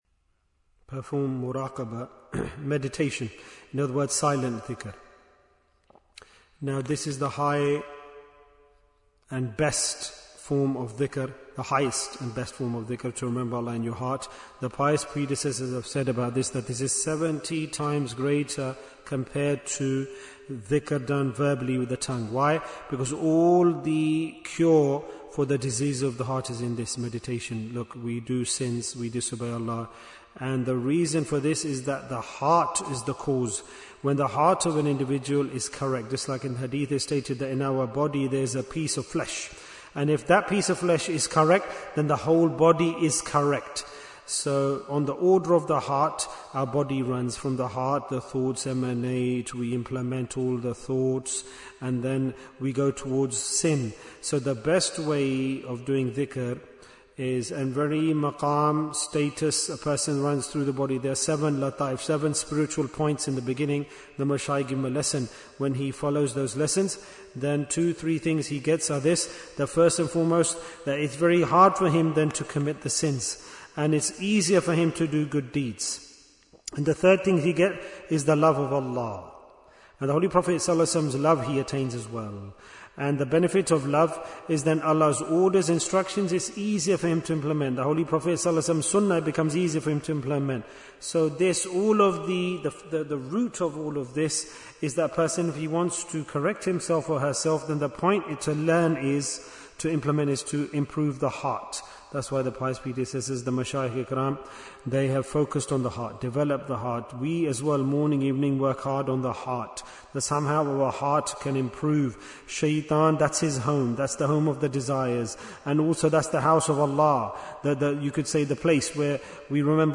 - Part 16 Bayan, 31 minutes 2nd February, 2026 Click for Urdu Download Audio Comments Why is Tazkiyyah Important? - Part 16 Muraqabah is the best form of Dhikr.